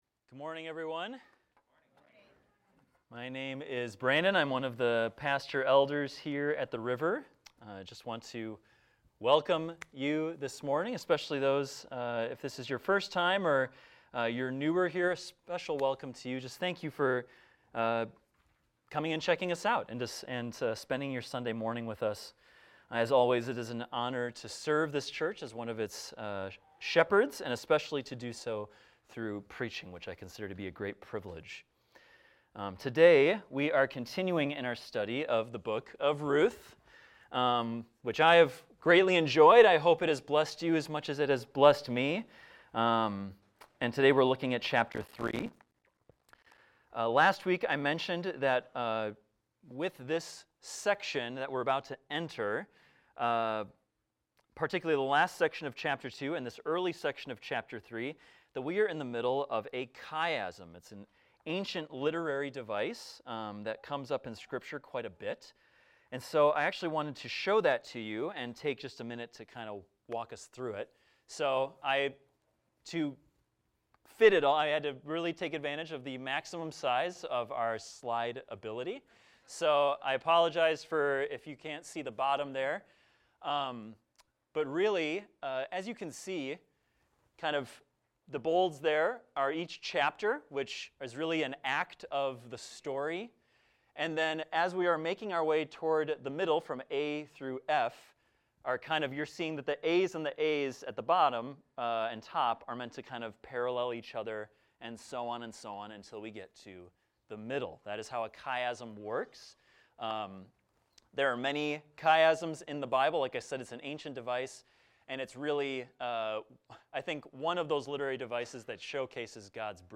A sermon on Ruth 3 titled, "A Worthy Woman"